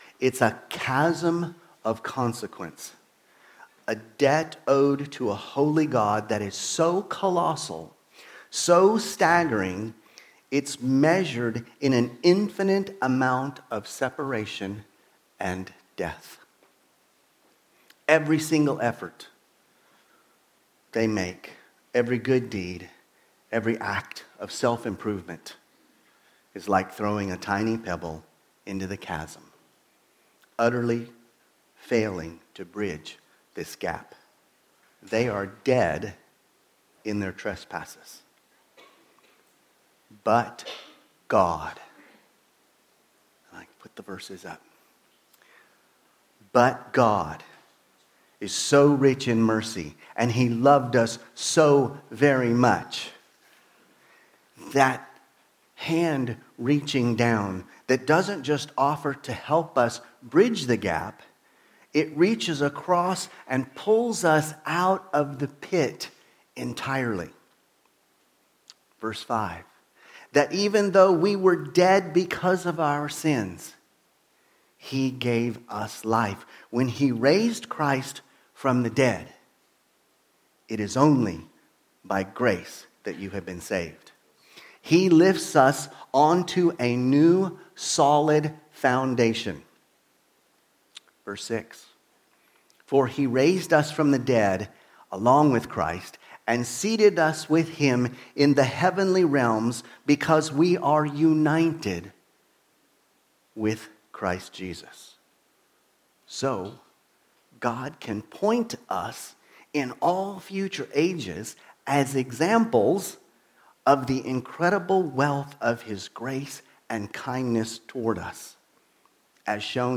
From Series: "Sunday Service"